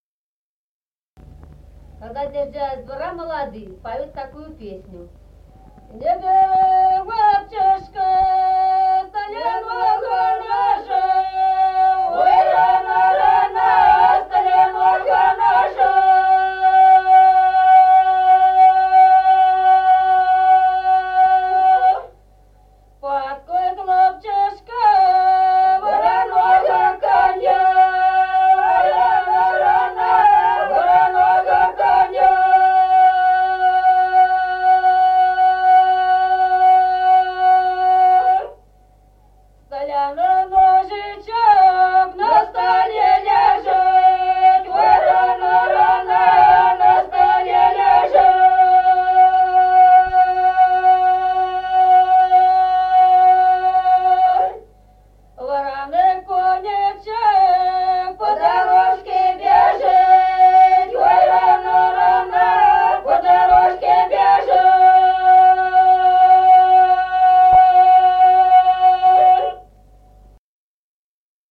Народные песни Стародубского района «Не бери, мальчишка», свадебная, поют, когда молодые съезжают со двора.
(запев)
(подголосник)
1953 г., с. Остроглядово.